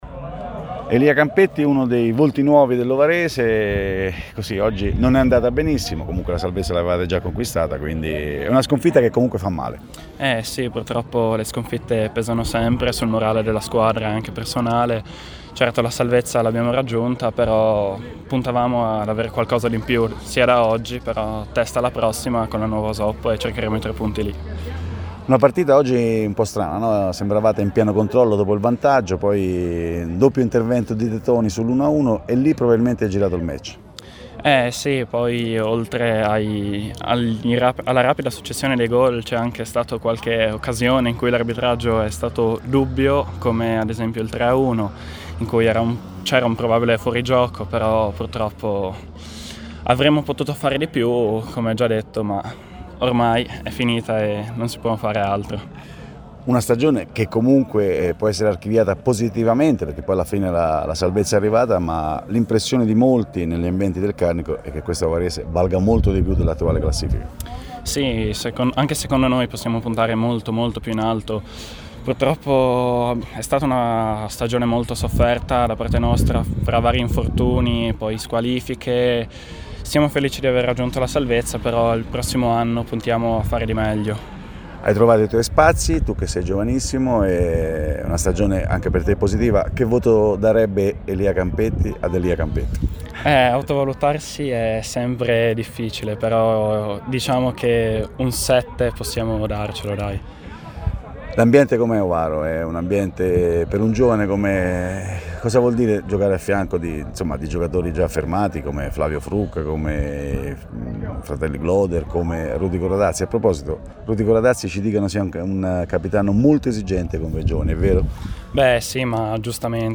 Proponiamo l’intervista realizzata al termine di Cercivento-Ovarese